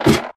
ladder5.ogg